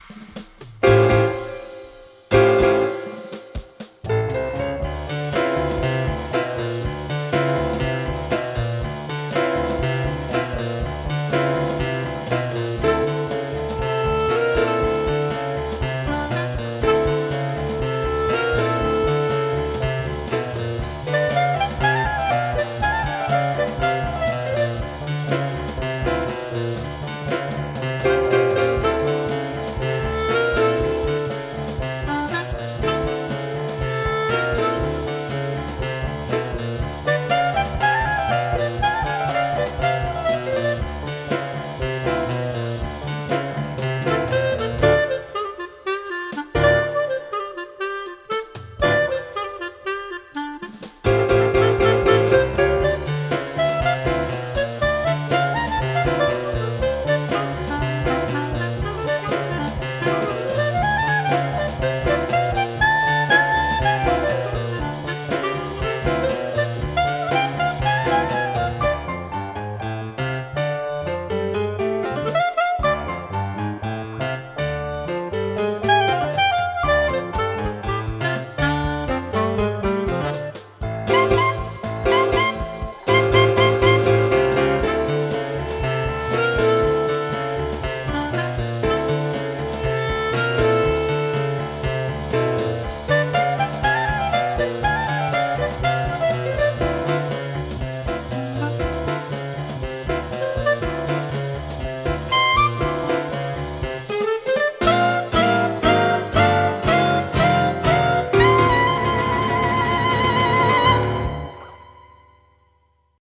Scored for Bb clarinet soloist and piano accompaniment.